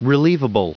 Prononciation du mot relievable en anglais (fichier audio)
Prononciation du mot : relievable